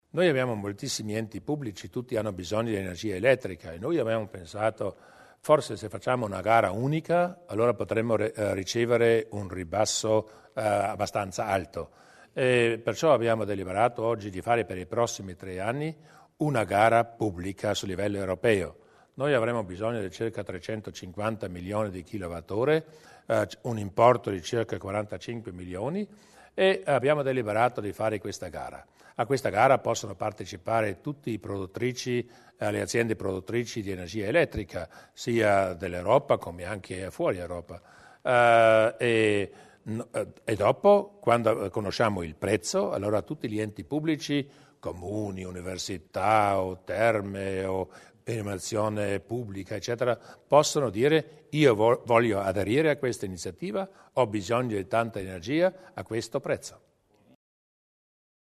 Il Presidente Durnwalder sul bando per la fornitura di energia elettrica